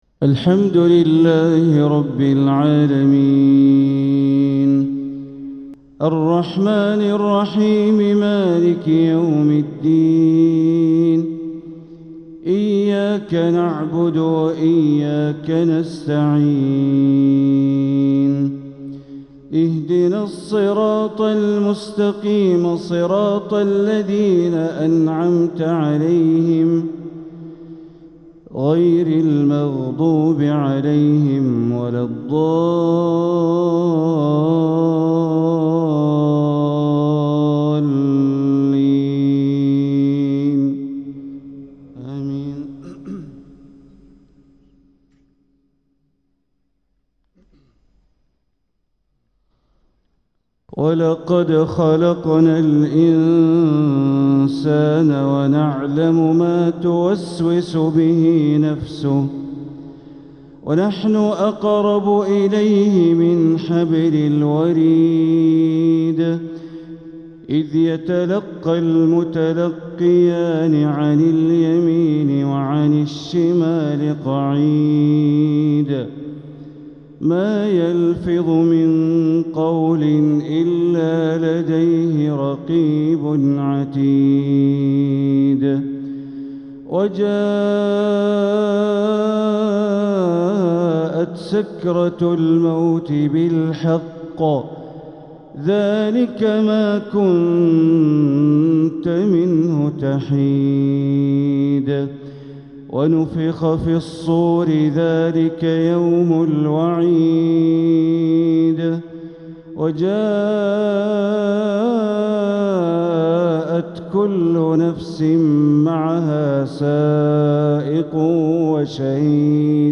تلاوة من سورة ق ١٦-٤٥ | فجر الجمعة ١٨ ربيع الآخر ١٤٤٧ > 1447هـ > الفروض - تلاوات بندر بليلة